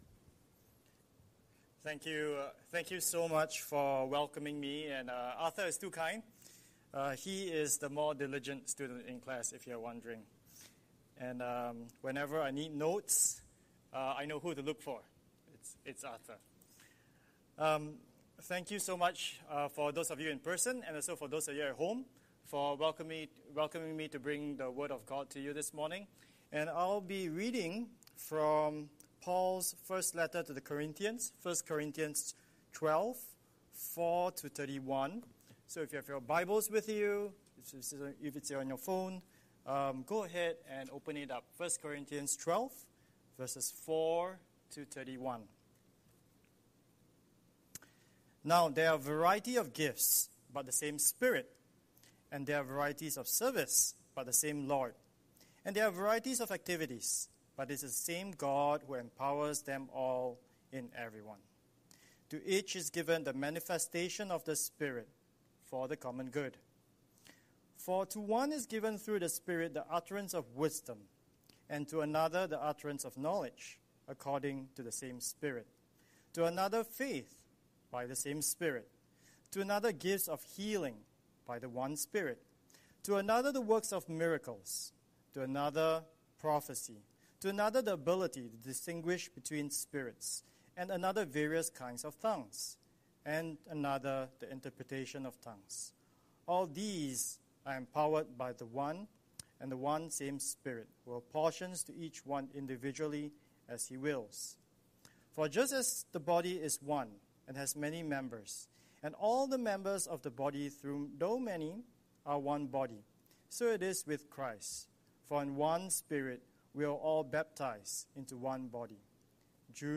Scripture: 1 Corinthians 12:4-31 Series: Sunday Sermon